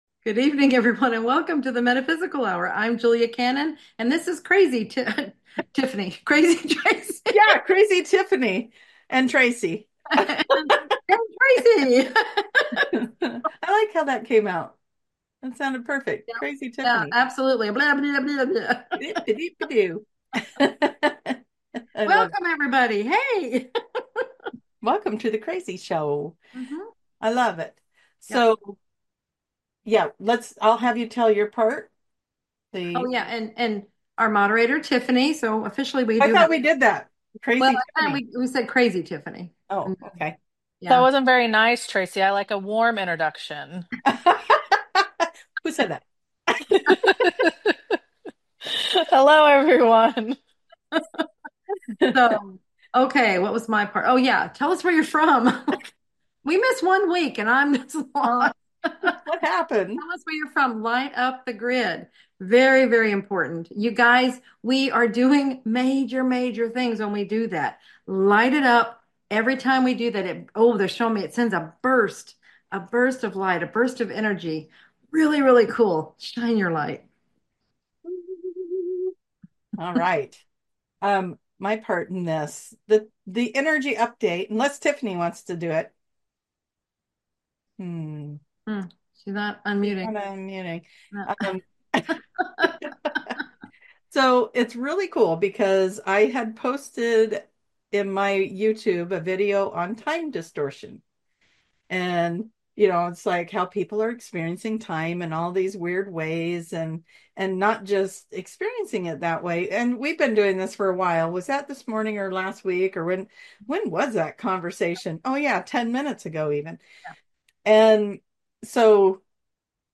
Answering viewer questions